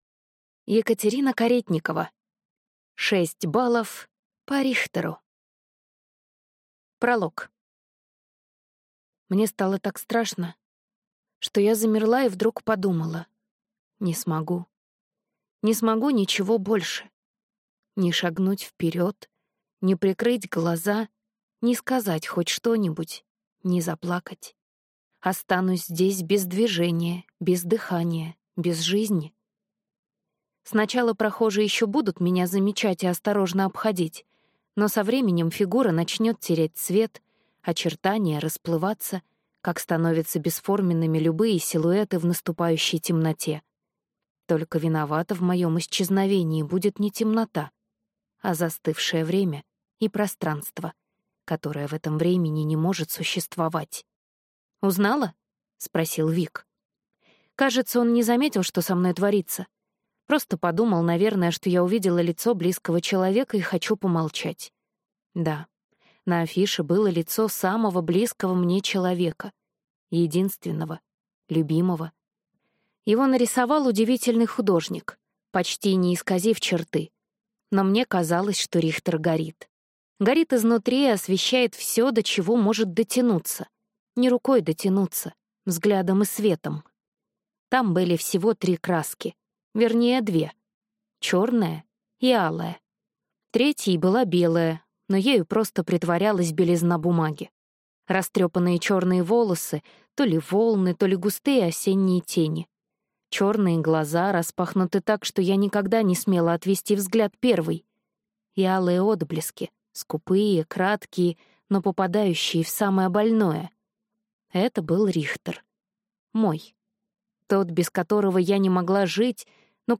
Аудиокнига Шесть баллов по Рихтеру | Библиотека аудиокниг